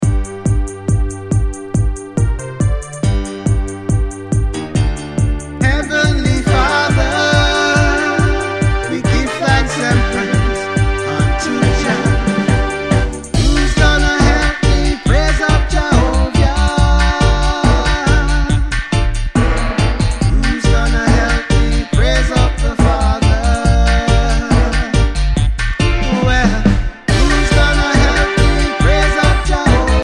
Reggae Ska Dancehall Roots Vinyl Schallplatten Records ...